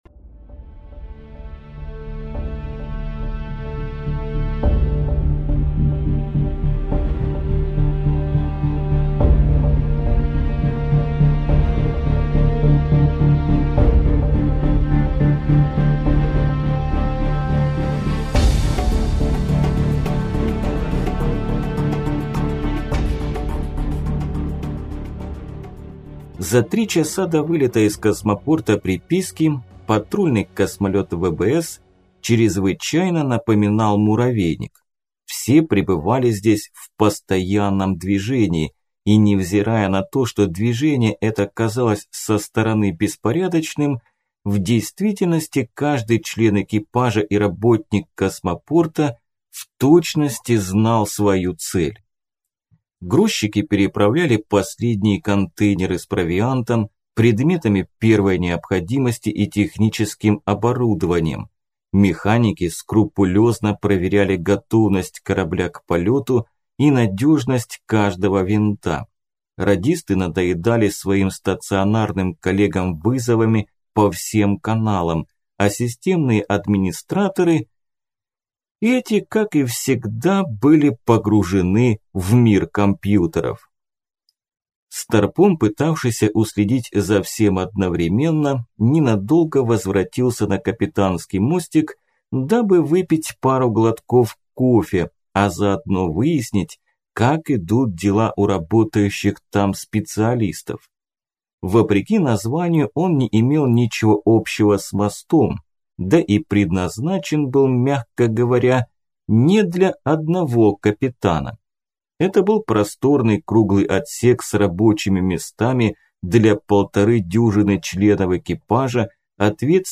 Аудиокнига Опальный капитан.